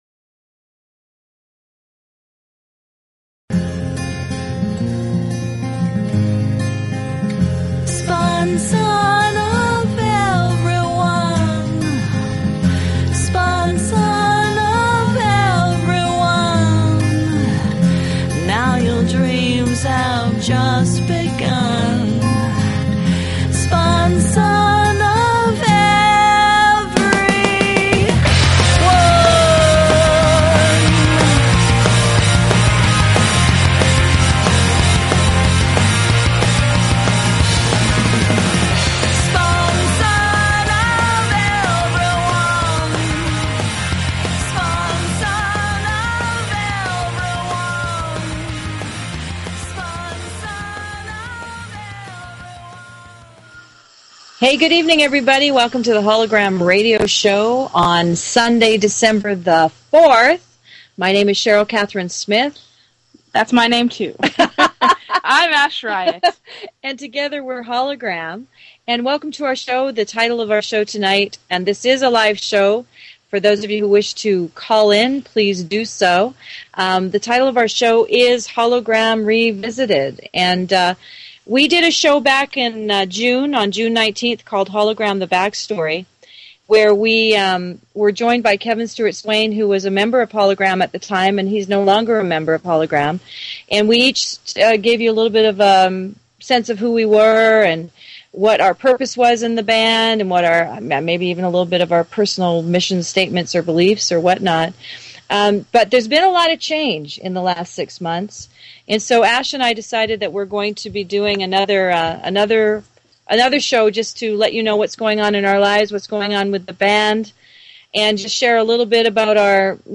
Talk Show Episode, Audio Podcast, Hollow-Gram_Radio_Show and Courtesy of BBS Radio on , show guests , about , categorized as
They offer a unique radio show where they share their music, their sharp wit, and their visions for This planet.